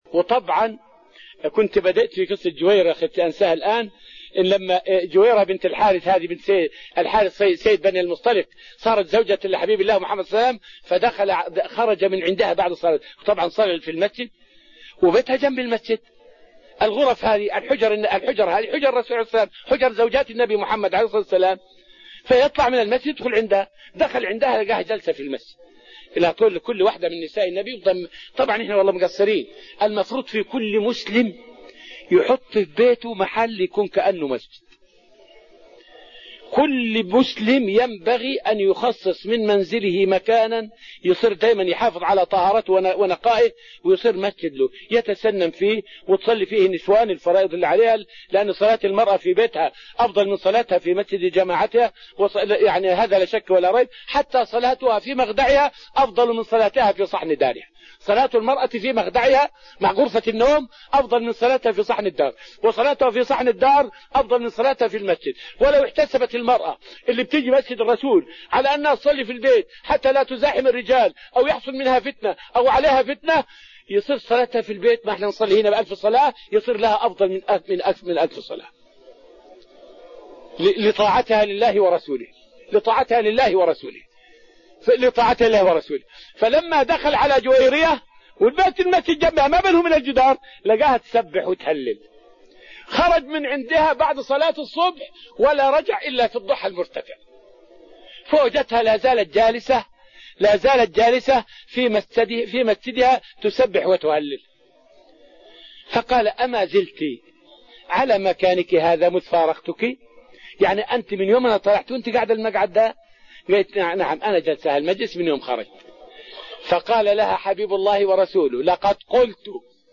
فائدة من الدرس الخامس من دروس تفسير سورة النجم والتي ألقيت في المسجد النبوي الشريف حول المدن الثلاث التي تمسّكت بدين الله عز وجل بعد وفات النبي صلى الله عليه وسلم.